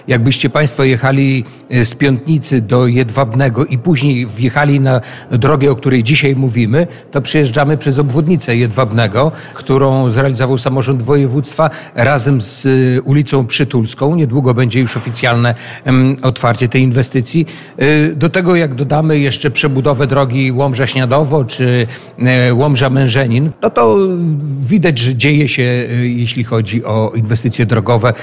Członek zarządu województwa podlaskiego Jacek Piorunek podkreślił, że, jeżeli chodzi o inwestycje drogowe w całym województwie, dzieje się bardzo dużo.